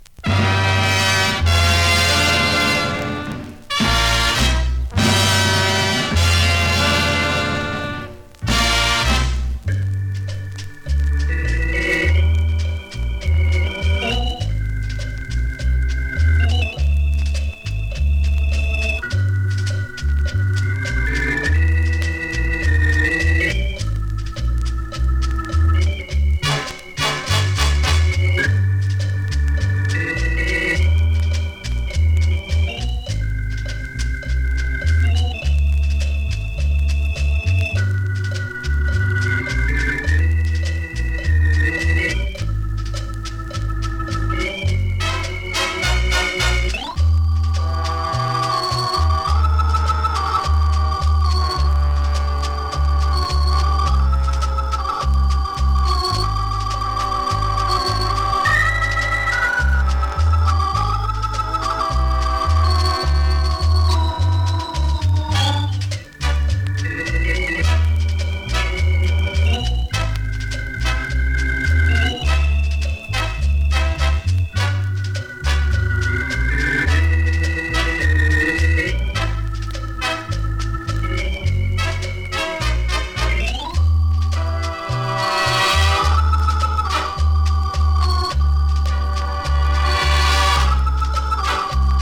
スリキズ、ノイズかなり少なめの
B面の試聴はこちらからどうぞ。